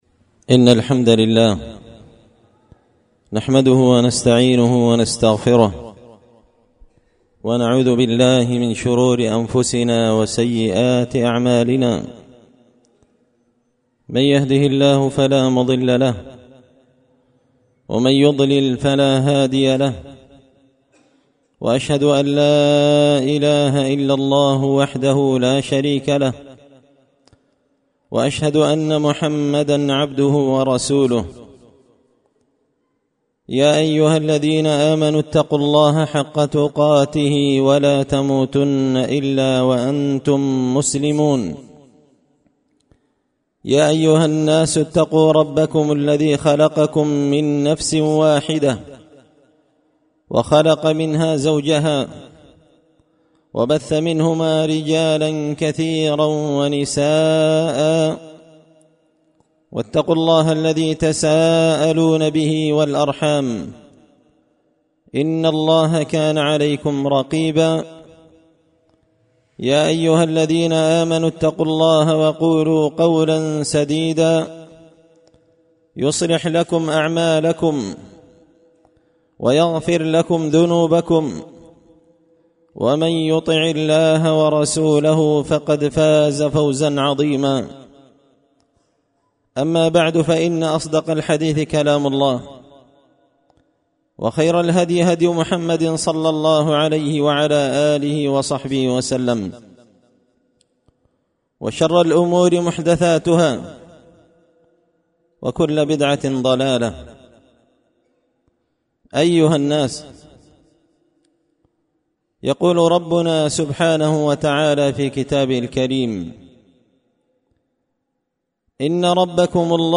خطبة جمعة بعنوان – الرحمن على العرش استوى
دار الحديث بمسجد الفرقان ـ قشن ـ المهرة ـ اليمن